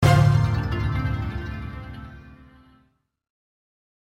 Звуки выигрыша, победы
На этой странице собраны энергичные звуки победы и выигрыша — от фанфар до коротких мелодичных оповещений.
Детский вариант